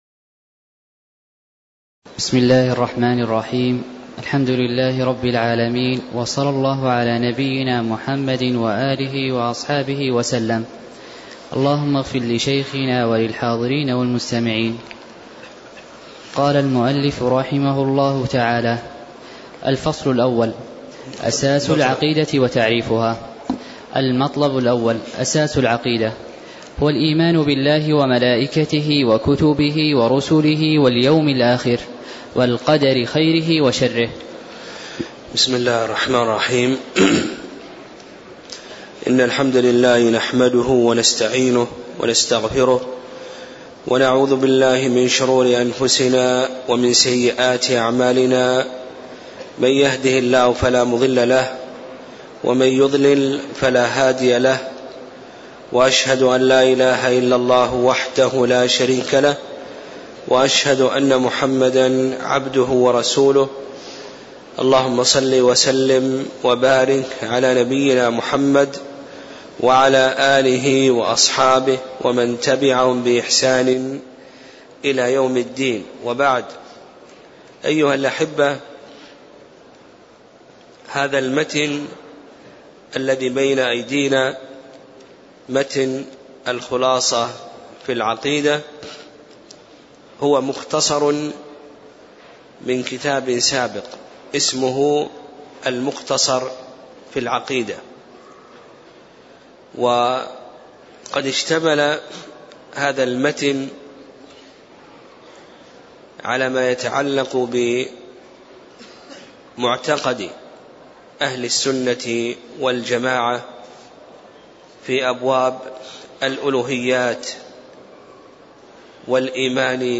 تاريخ النشر ١٩ شعبان ١٤٣٦ هـ المكان: المسجد النبوي الشيخ